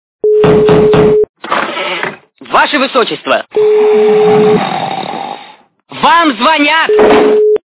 » Звуки » Смешные » Bаше, высочество. - Вам звонят
При прослушивании Bаше, высочество. - Вам звонят качество понижено и присутствуют гудки.